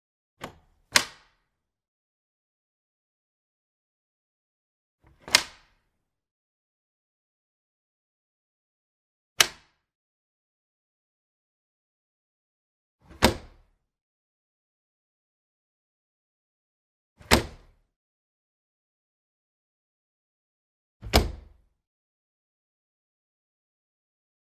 Plastic Vinyl Casement Window Unlock Sound
household